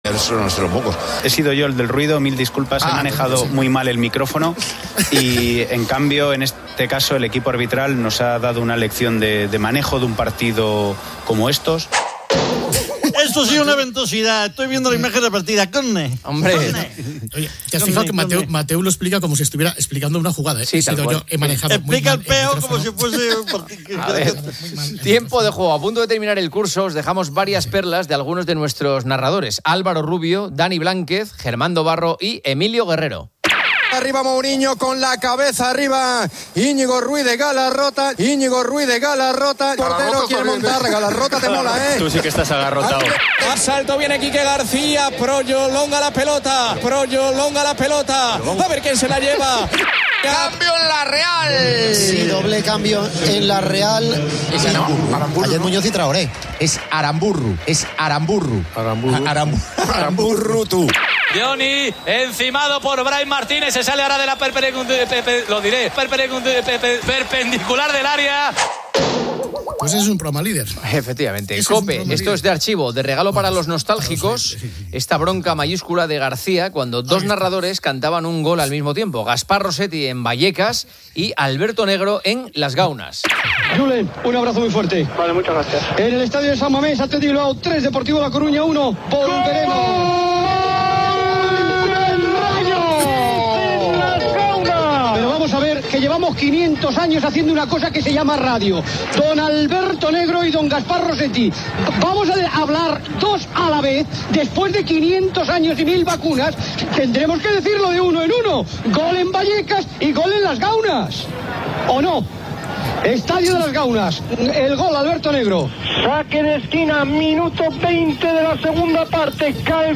Tiempo de juego, a punto de terminar el curso, os dejamos varias perlas de algunos de nuestros narradores: